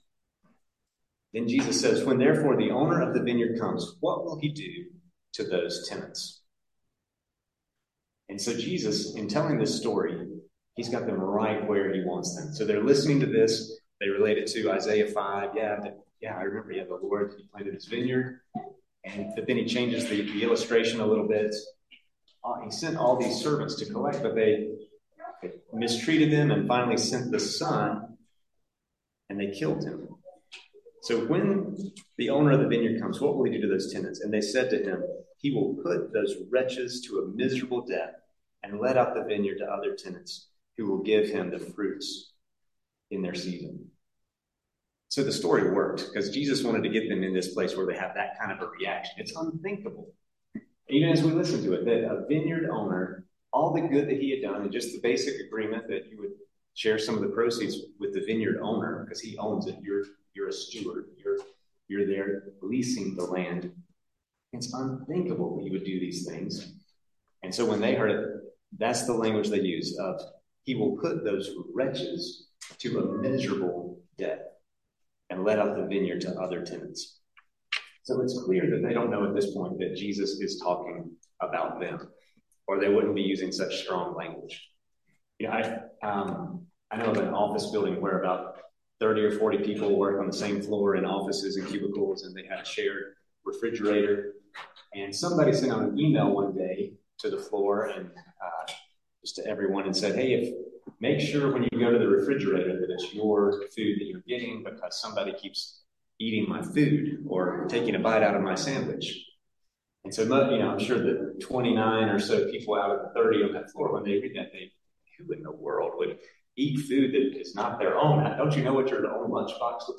Sermons | Christ the King